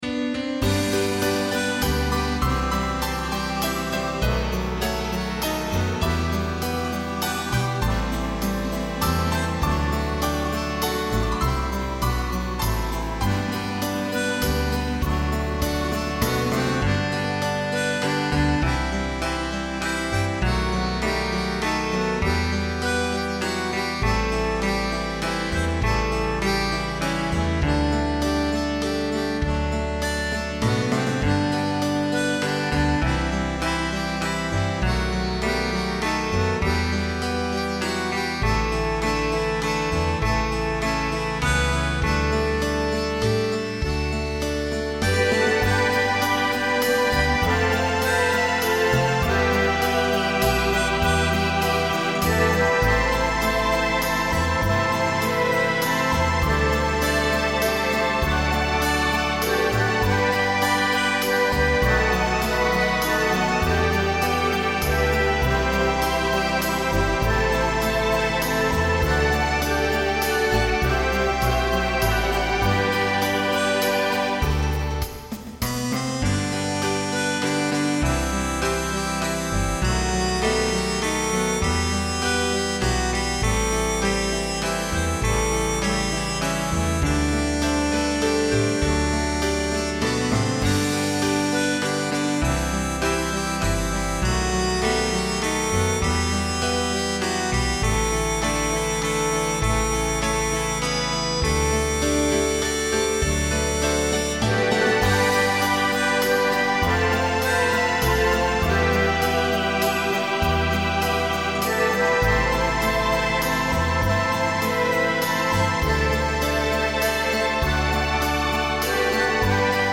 I tried to reflect the sense of desperation in the music.